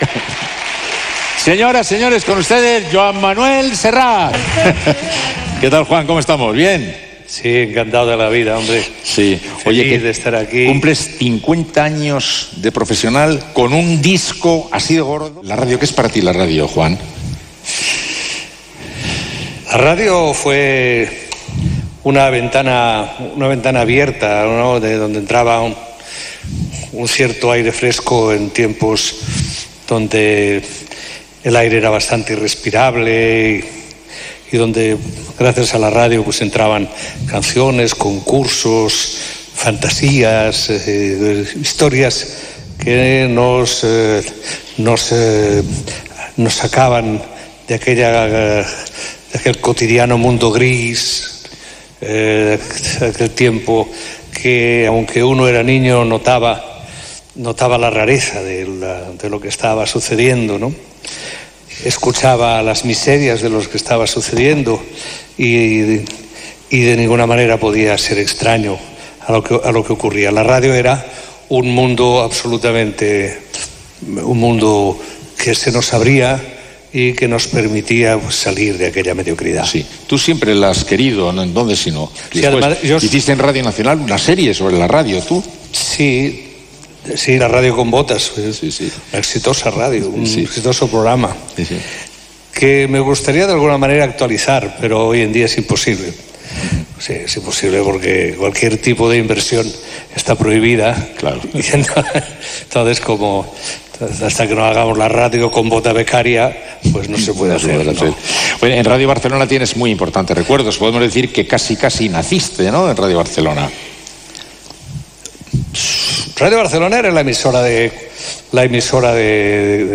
Gala del 90 aniversari de Ràdio Barcelona feta des del Palau de la Música. Entrevista al cantant Joan Manuel Serrat.
Entreteniment